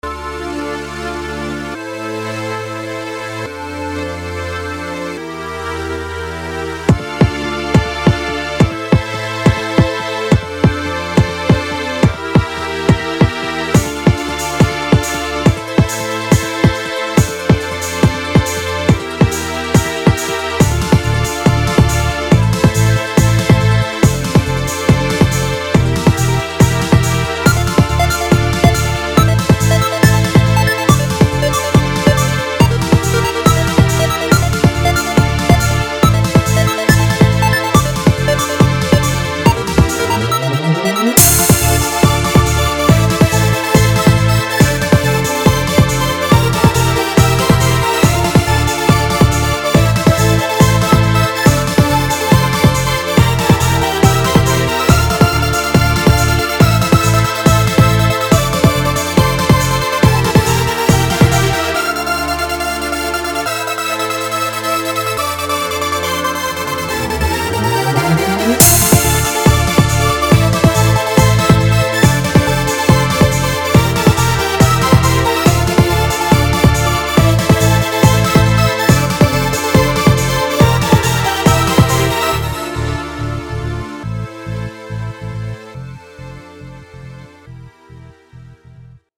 New future battle song :D